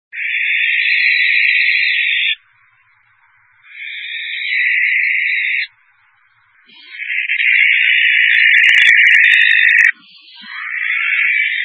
La Chouette effraie